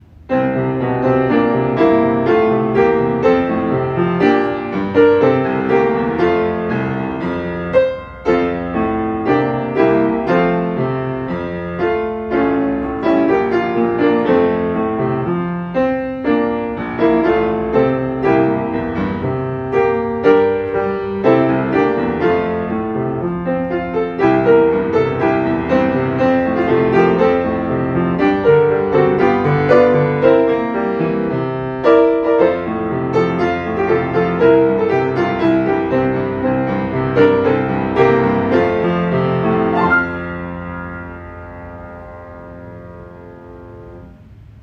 シンガポール日本人学校校歌（ピアノ独奏）.m4a